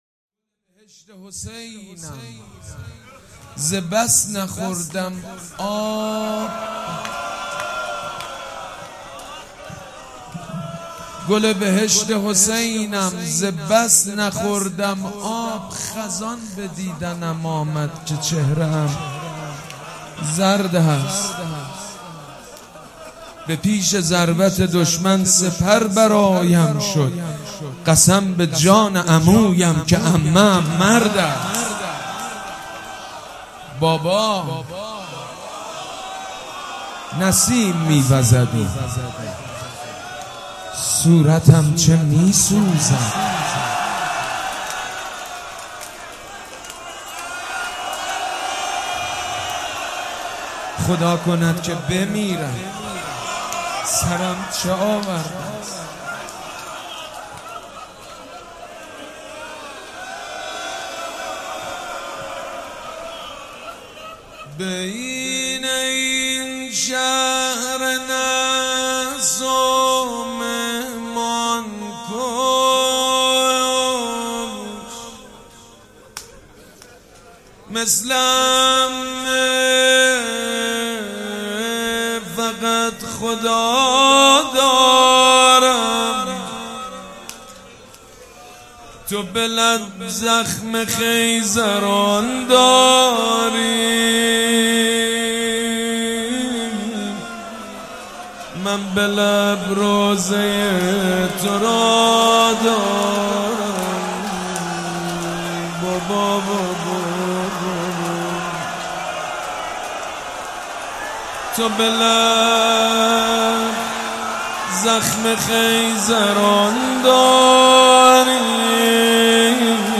مداحی جدید حاج سید مجید بنی فاطمه حسینیه ی ریحانه الحسین شب سوم محرم97